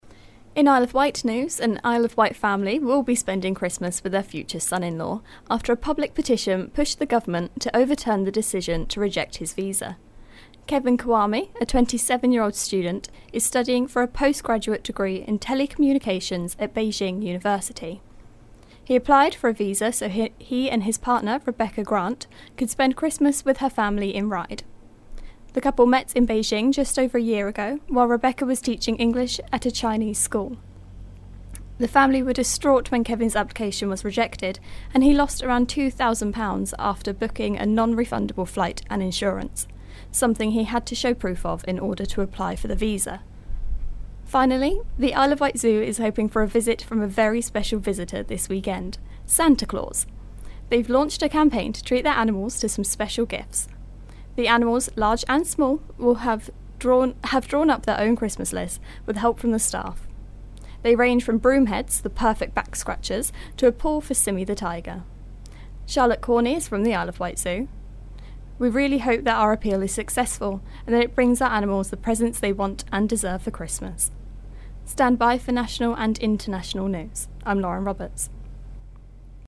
The local news headlines for 21/12/16